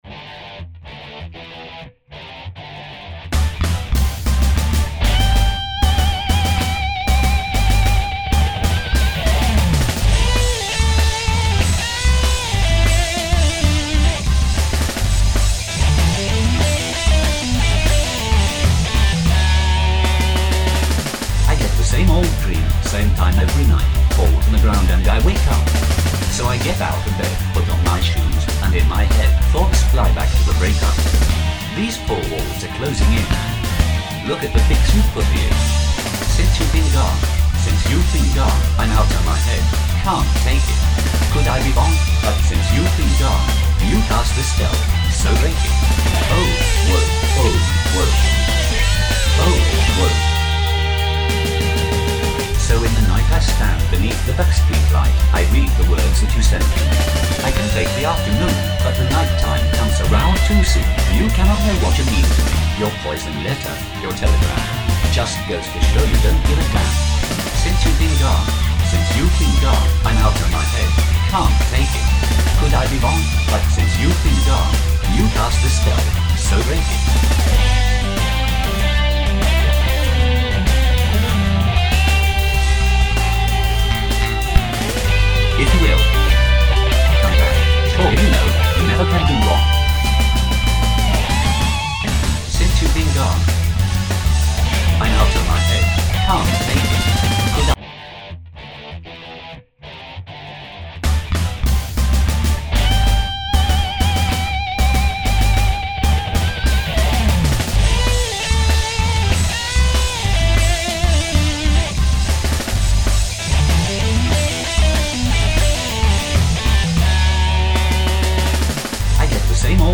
(cover version)
rock